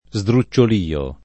sdrucciolio